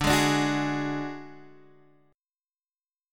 D Major Flat 5th